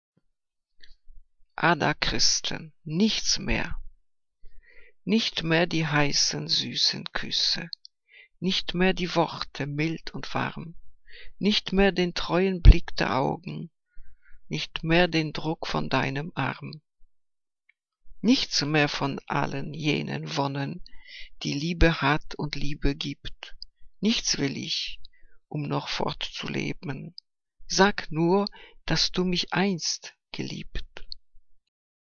Liebeslyrik deutscher Dichter und Dichterinnen - gesprochen (Ada Christen)